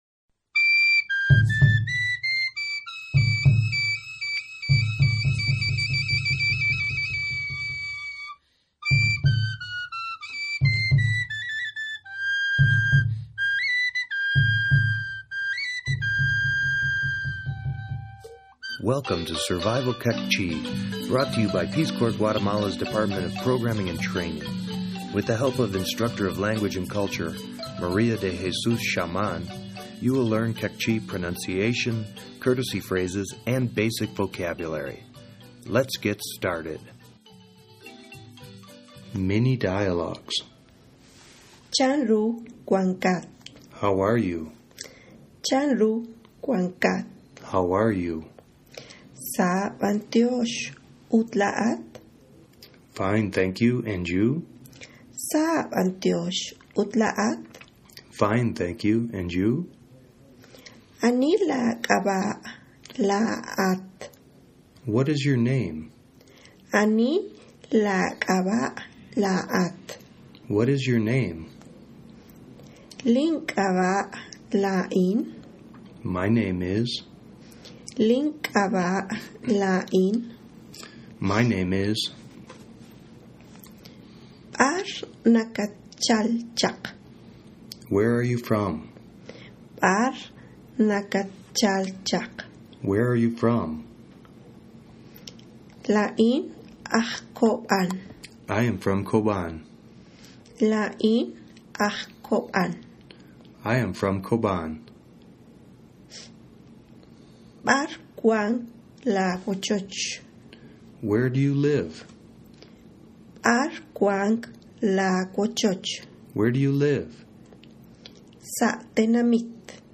Survival Queqchi - Lesson 02 - Dialogues, Introductions, Ask Information_.mp3